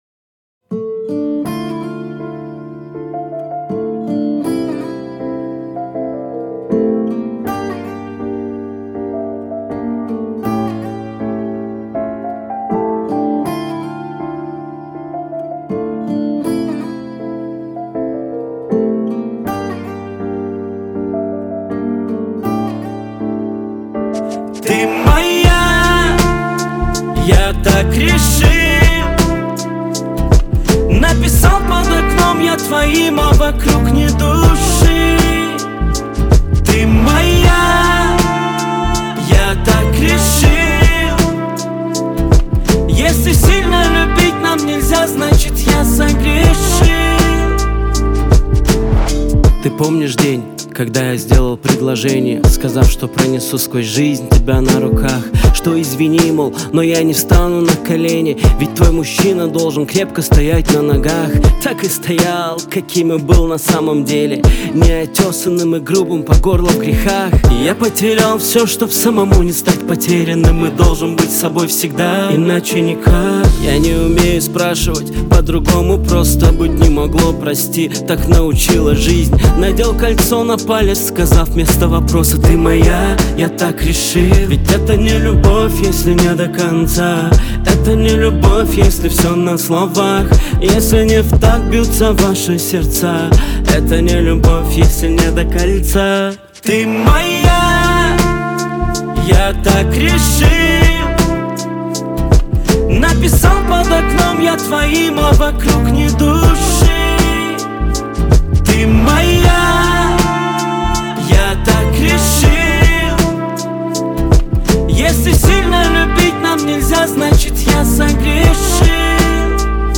это трек в жанре рэп с элементами R&B